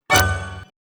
UI_SFX_Pack_61_14.wav